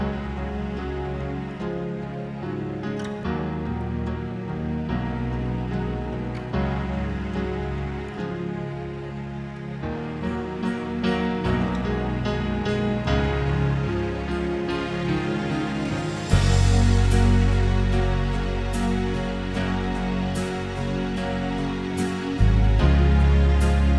Two Semitones Down Pop (2000s) 5:09 Buy £1.50